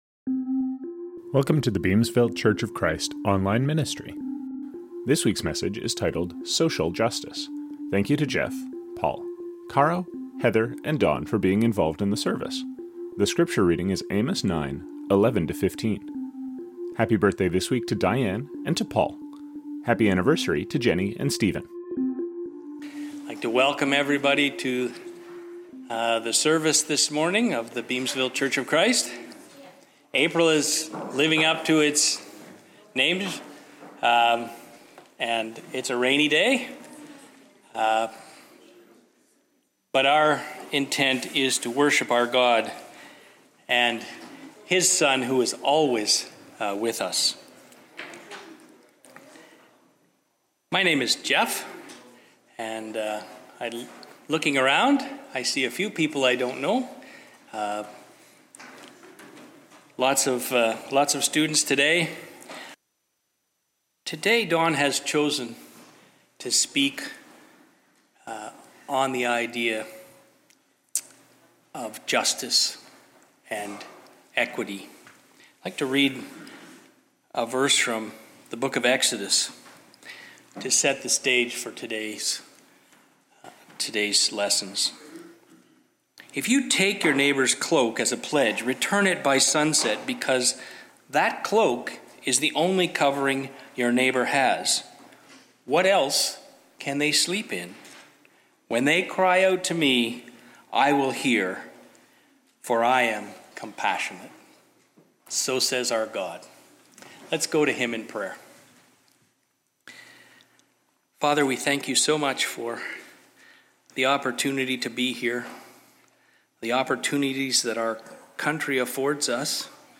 The scripture reading is Amos 9:11-15.